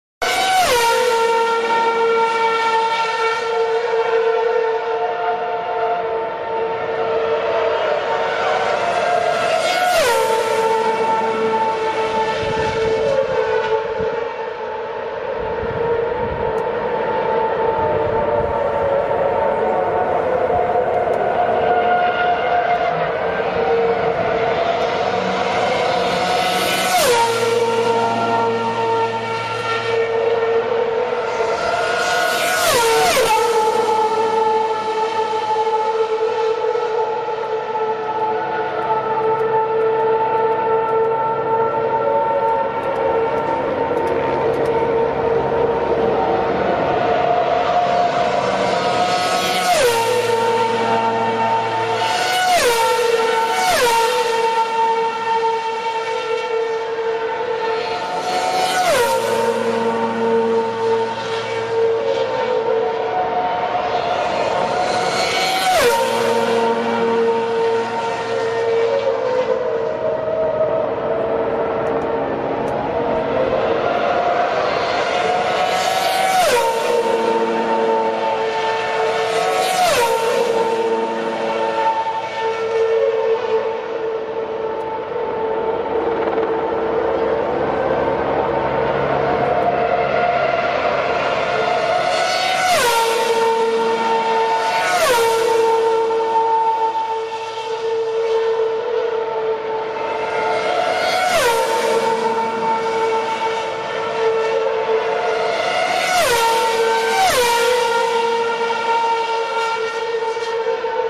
car-sound.mp3